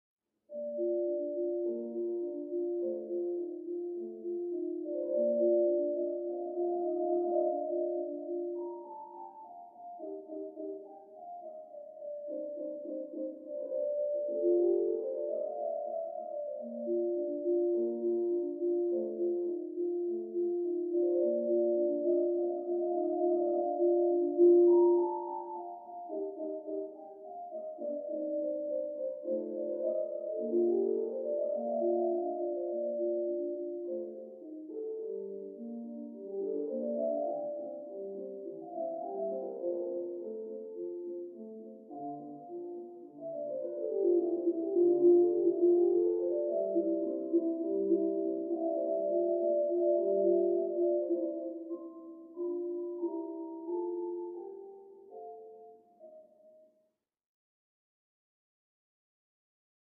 Music; Classical Piano, From Down Hallway.